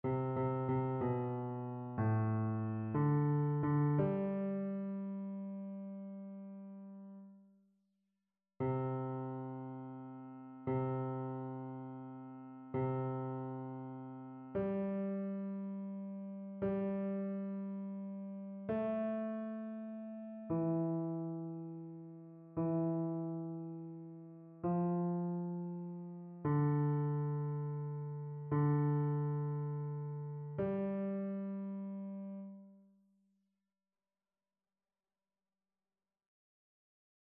Basse
annee-a-temps-ordinaire-saint-sacrement-psaume-147-basse.mp3